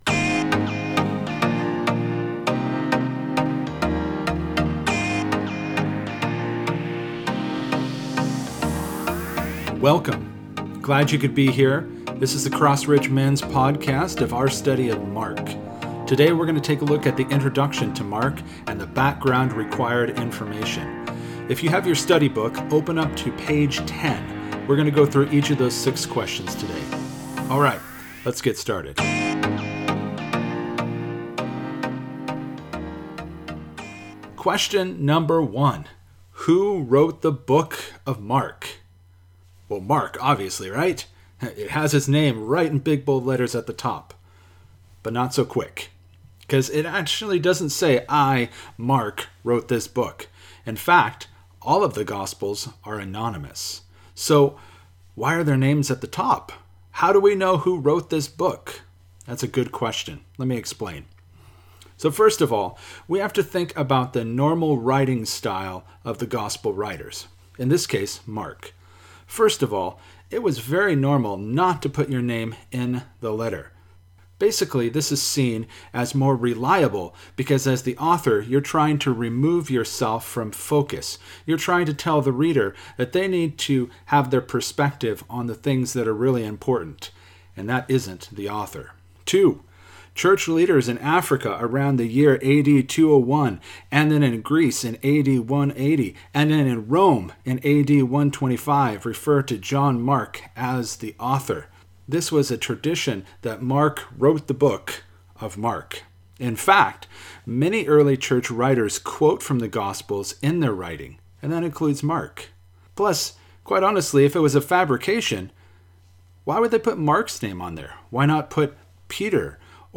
Teaching podcast for the Intro night of our Mens Study of the Gospel of Mark.